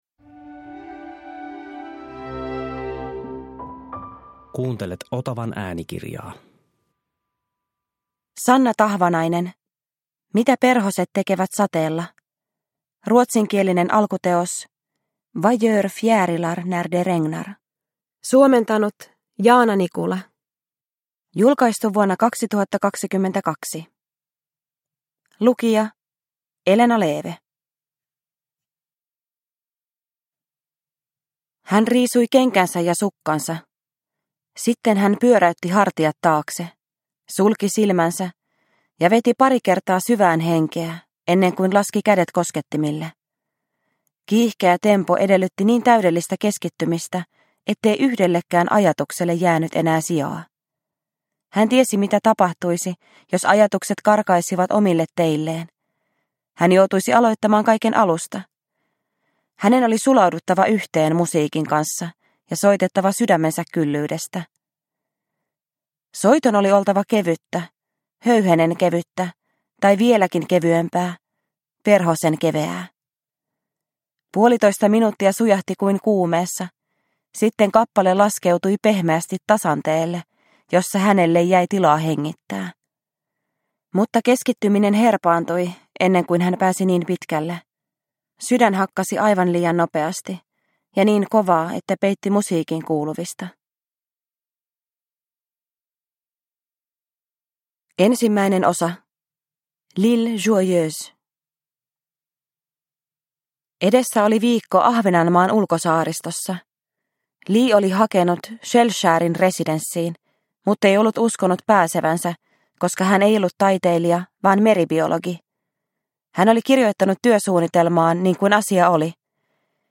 Mitä perhoset tekevät sateella? – Ljudbok – Laddas ner
Uppläsare: Elena Leeve